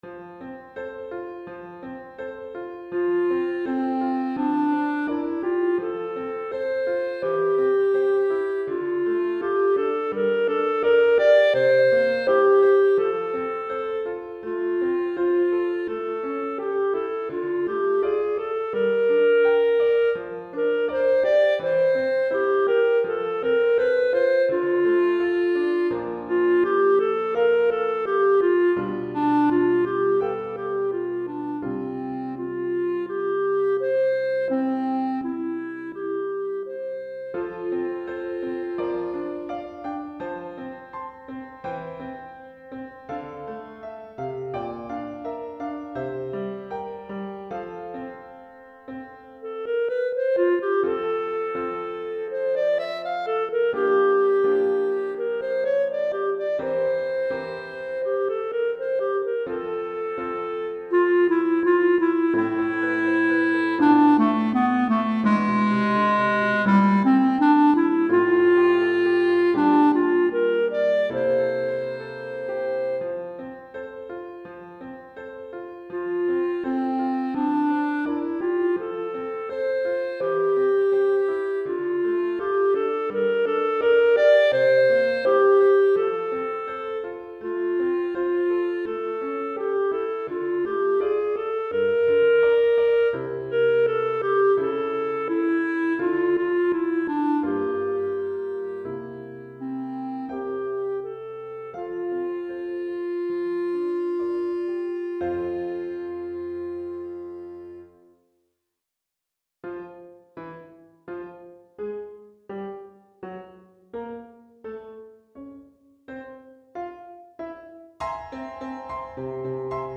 Pour clarinette et piano DEGRE CYCLE 2